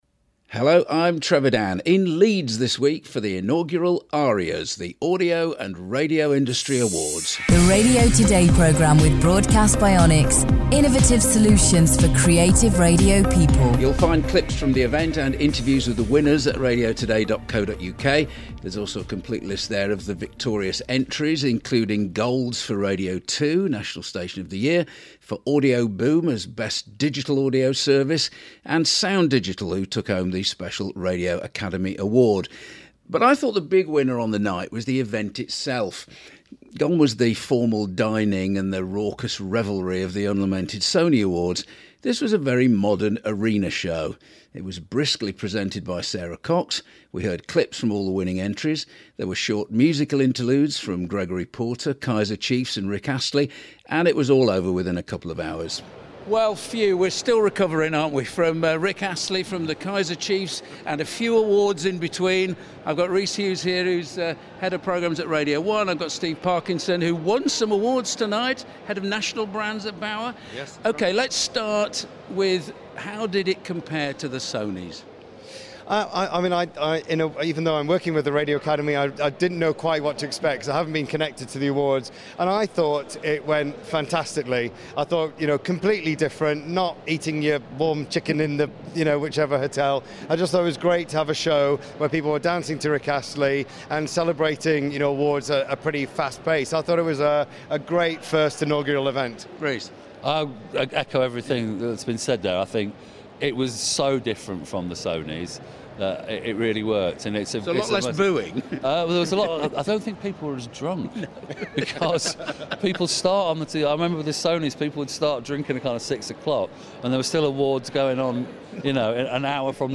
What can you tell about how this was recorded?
reports from the inaugural ARIAS, the UK Audio & Radio Industry Awards in Leeds. Plus news and Radio Moments